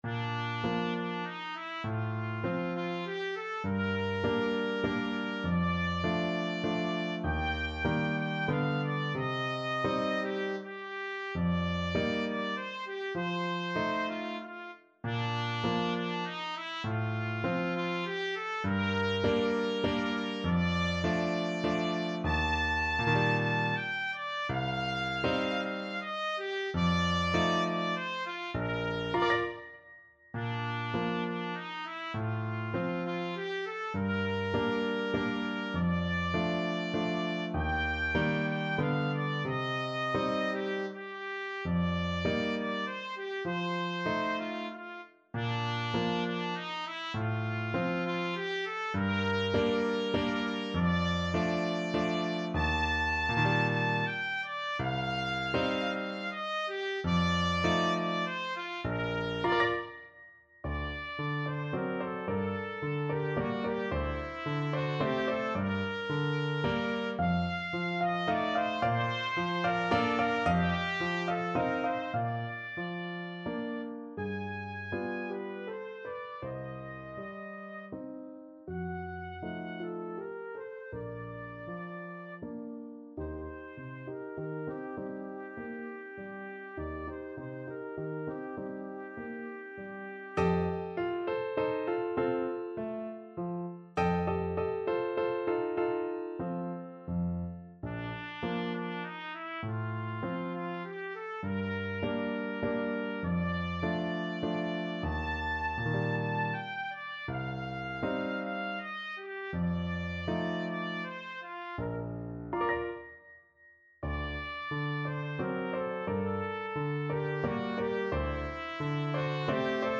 Classical Merikanto, Oskar Valse lente, Op.33 Trumpet version
Trumpet
Bb major (Sounding Pitch) C major (Trumpet in Bb) (View more Bb major Music for Trumpet )
3/4 (View more 3/4 Music)
~ = 100 Tranquillamente
Classical (View more Classical Trumpet Music)